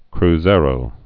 (kr-zârō, -zār)